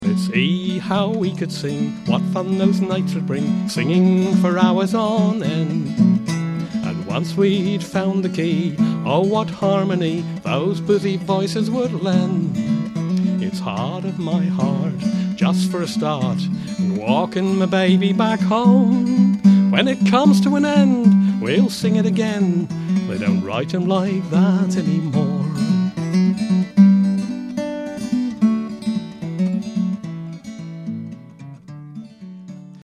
It's all at Ashington Folk Club!